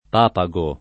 [ p # pa g o ]